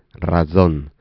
Predorsodental africada sorda [ts] Predorsodental africada sonora [dz]
razon    [raˈdzon]